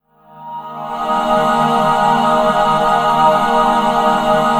45 PAD 1  -L.wav